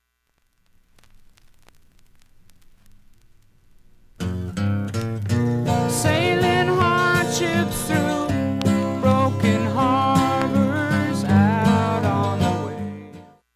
音質良好全曲試聴済み。
A-1始めにかすかなプツが２回出ます。